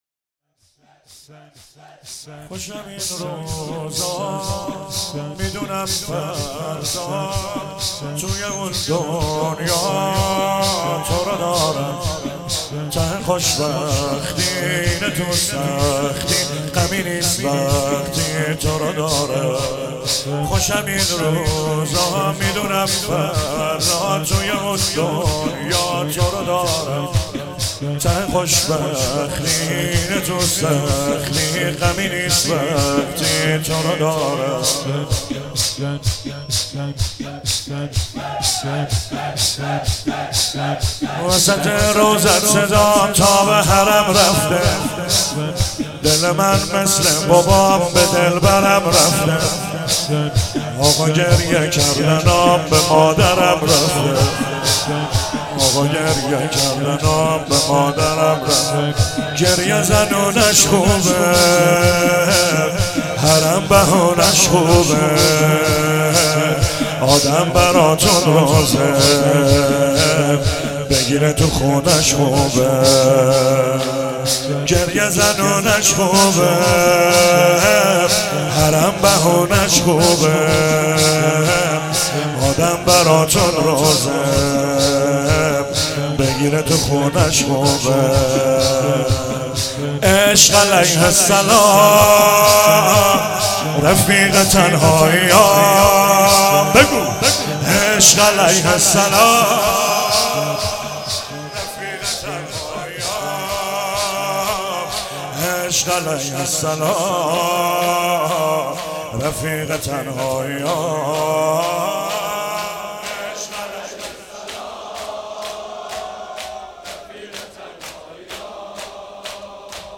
مراسم هفتگی 26 اسفند 95
چهاراه شهید شیرودی حسینیه حضرت زینب (سلام الله علیها)
شور